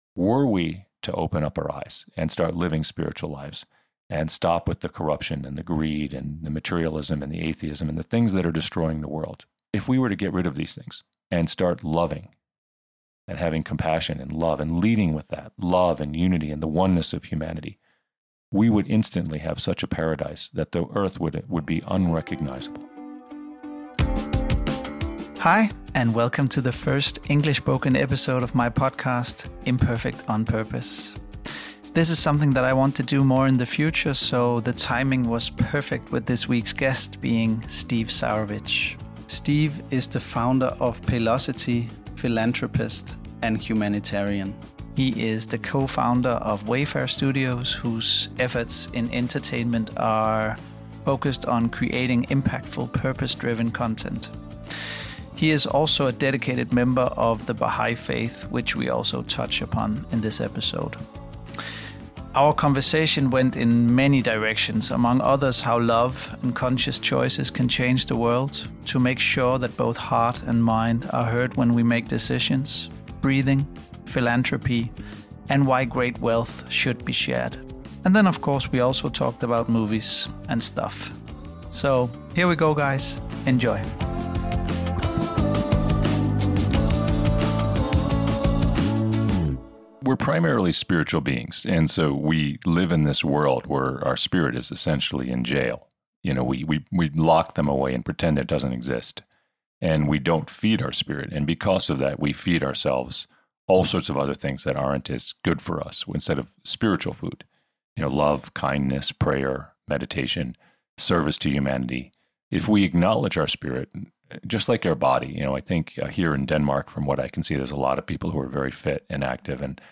A great day in the studio.